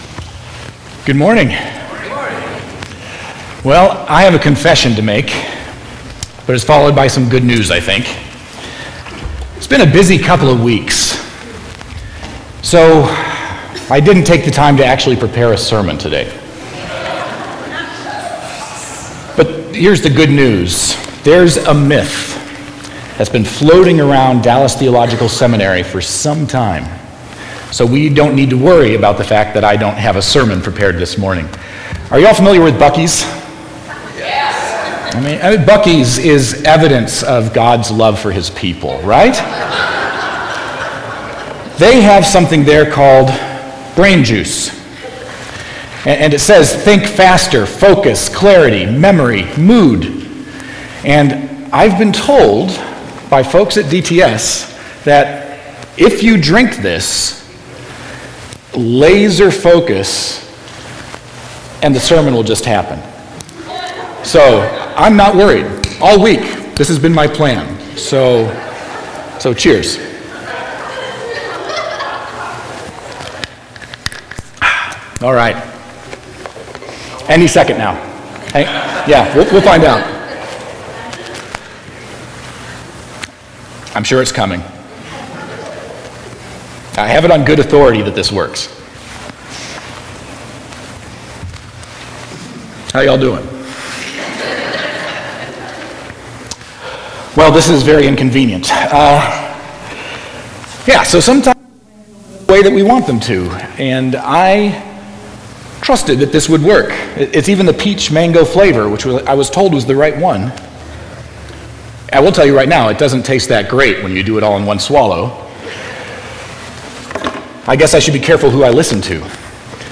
Sermon-2.23.20.mp3